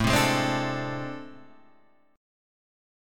AmM11 chord